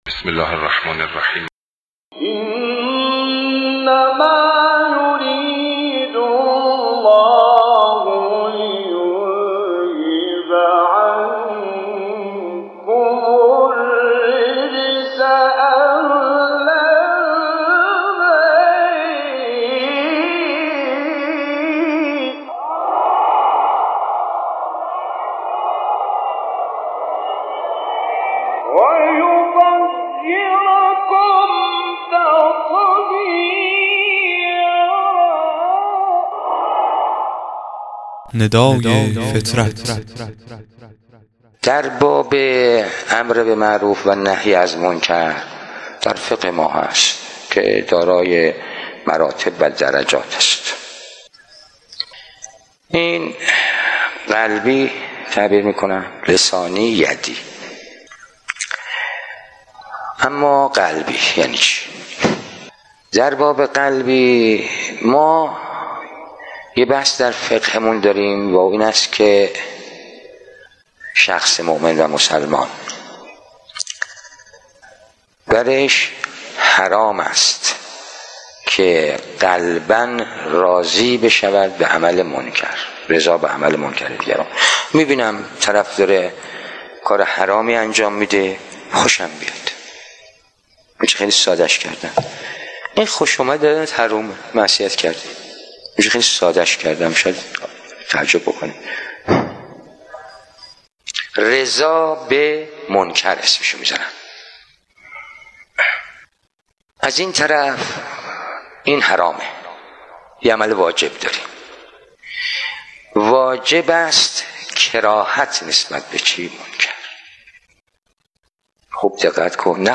قطعه صوتی کوتاه و زیبا از آیت الله مجتبی تهرانی(ره) در بیان یک مسئله شرعی